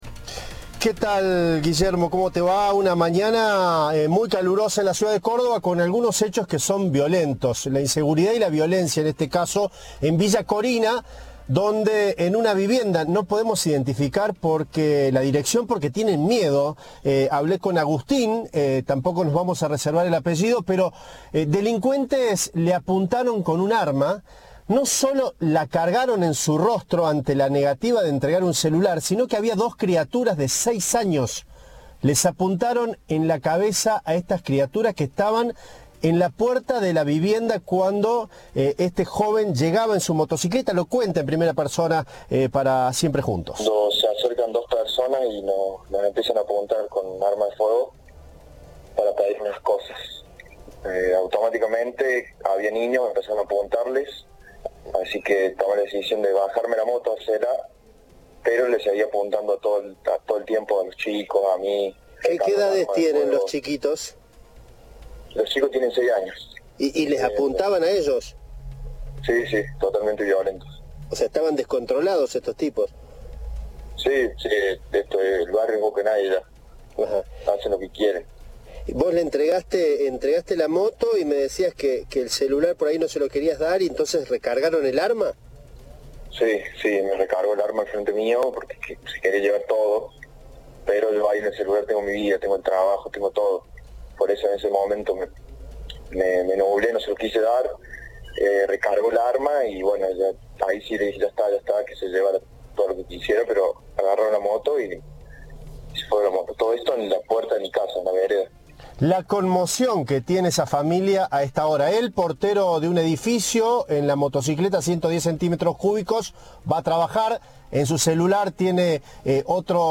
Los delincuentes le robaron la moto y el celular y escaparon. La víctima habló con Cadena 3 y relató los violentos hechos.
Informe